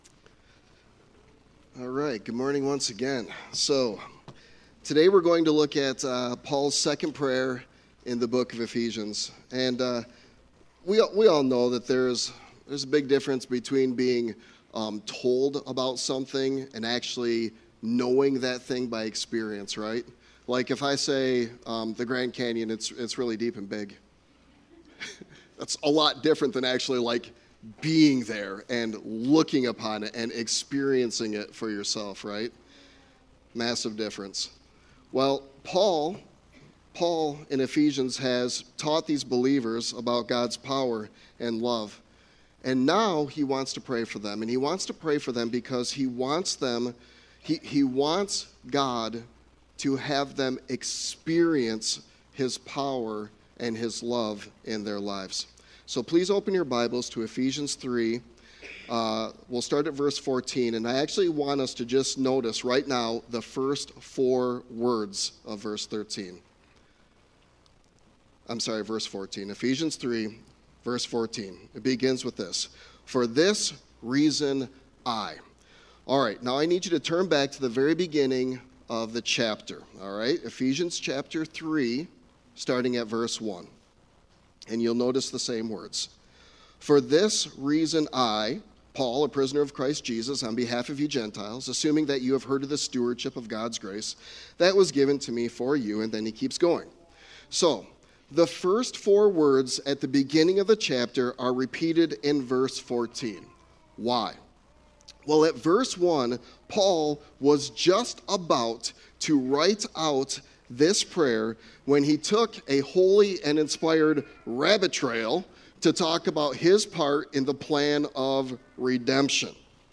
Sermon Text: Ephesians 3:14-21